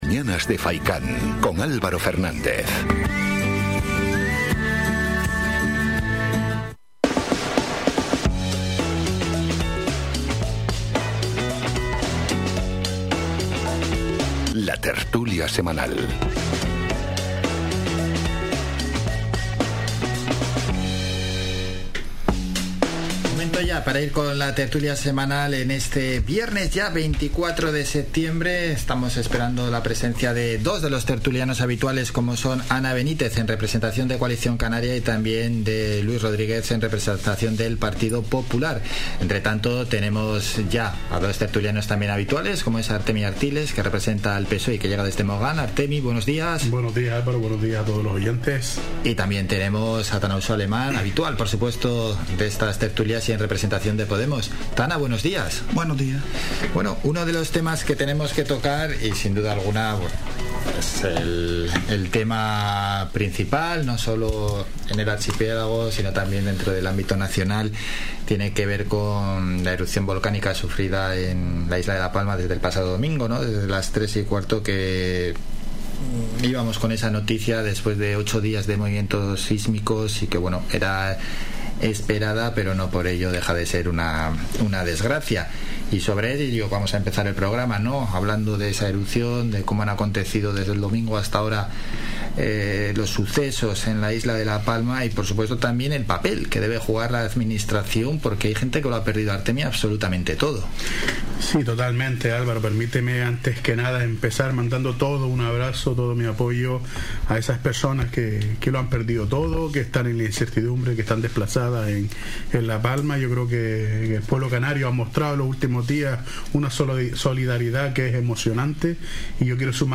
La tertulia semanal | Debate sobre la erupción del volcán en La Palma y las pateras rescatadas en el sur de Gran Canaria